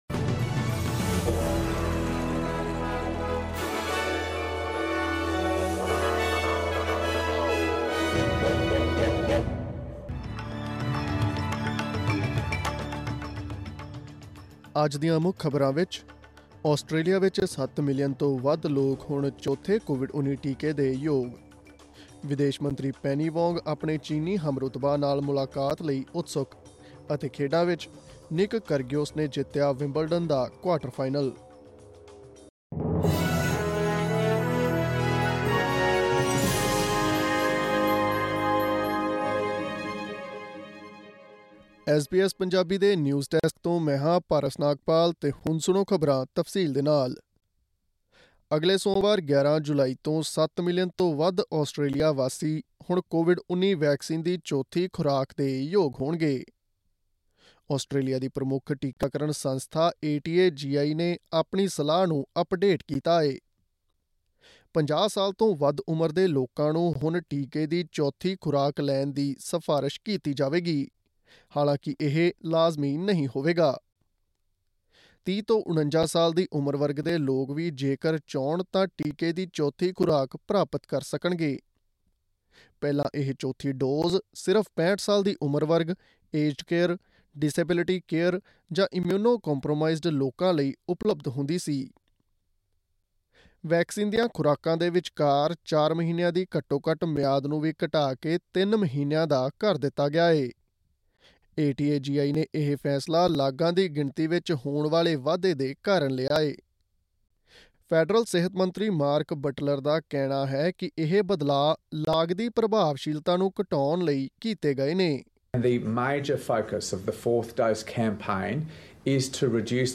Click on the audio button to listen to the news bulletin in Punjabi.